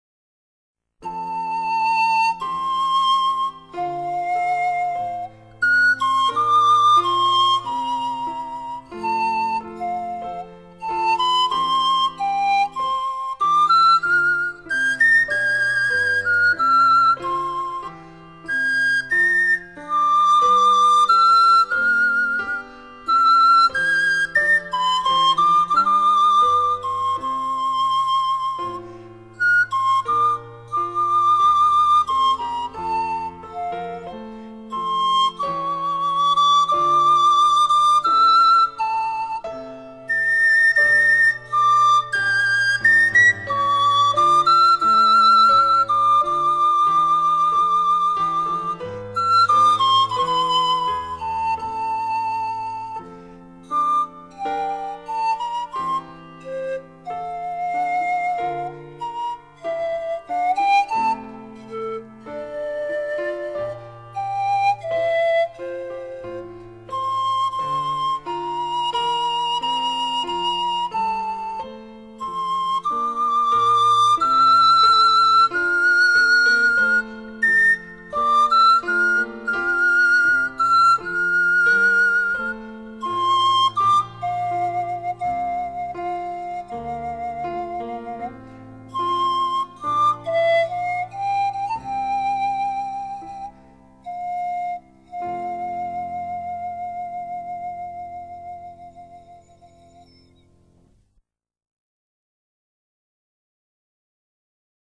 自然的乐器，充满了大自然奔放、和谐的意境，又具有一种太空般虚幻、飘渺的音色。